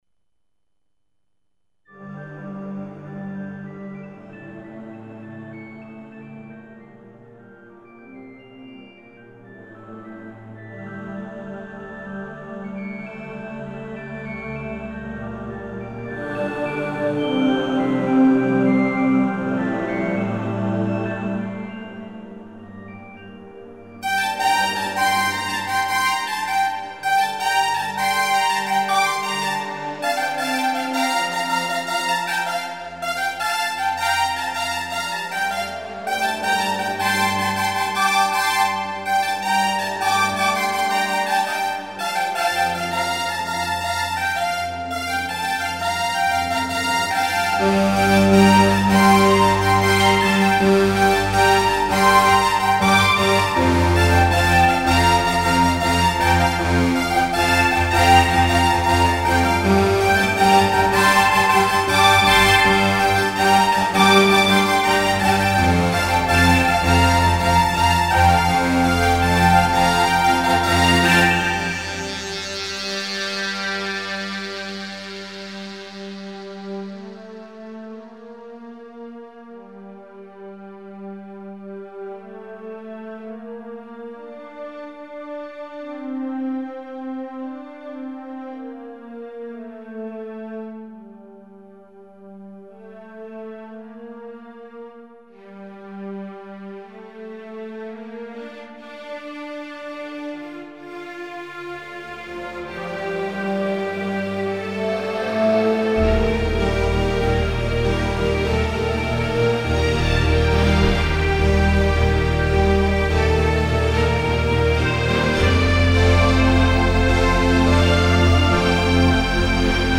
Complex. Symphonic piece in parts, more electronic in others. Epic.